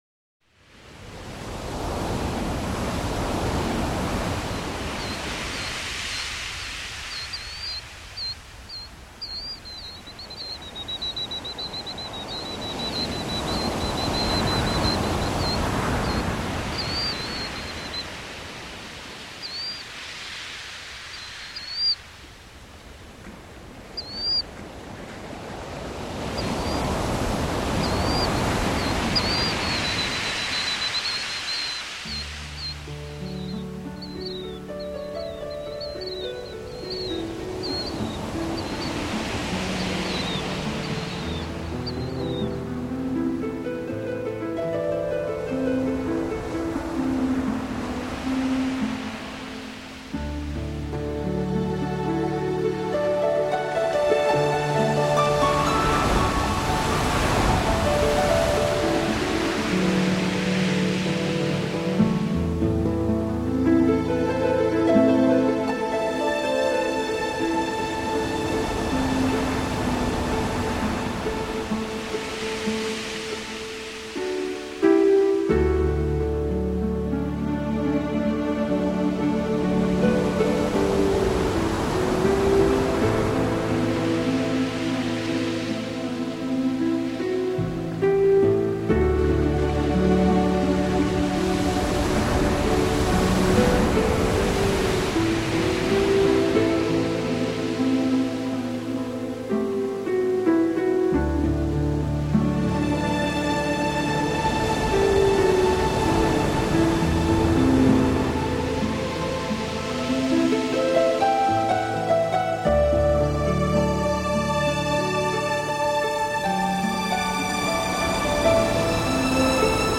音乐类型：新世纪音乐(NEW AGE)